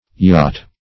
Yacht \Yacht\ (y[o^]t), n. [D. jagt, jacht; perhaps properly, a
yacht.mp3